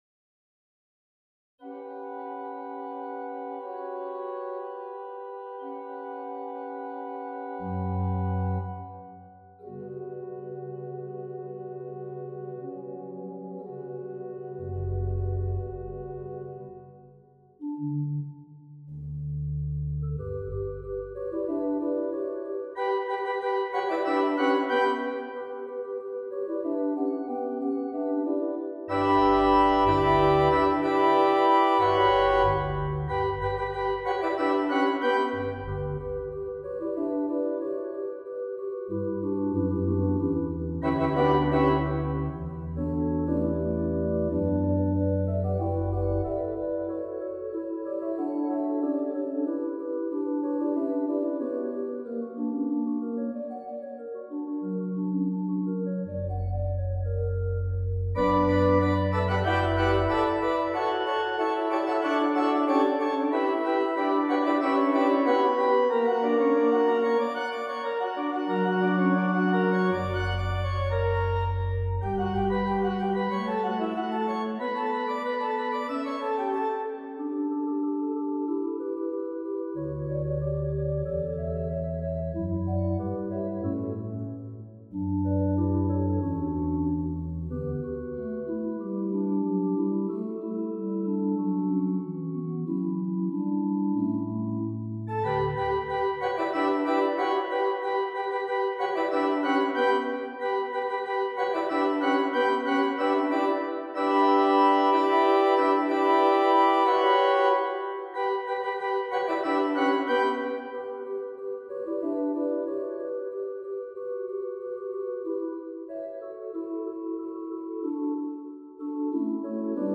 for organ A simple gesture of a repeated dyad with exterior voices above and below spins out this little "noel," titled in the older manner.